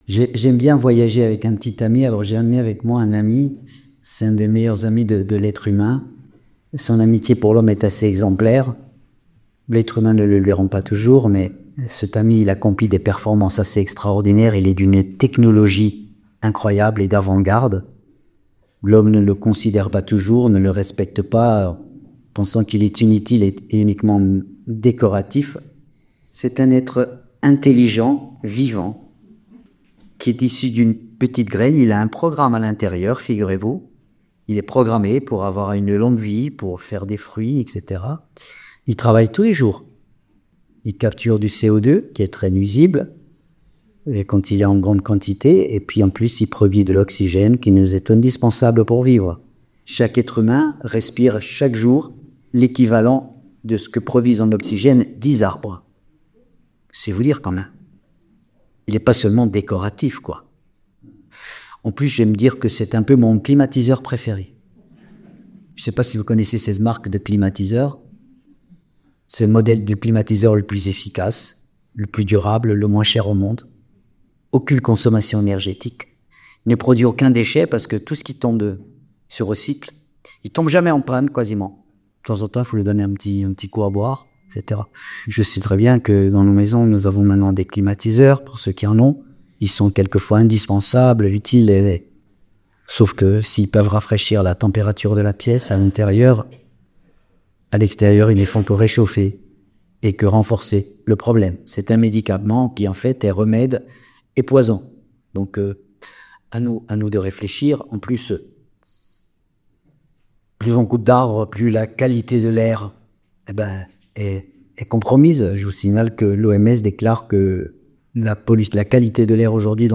Colomiers. Conf octobre 2024 avec Jean-François Bernardini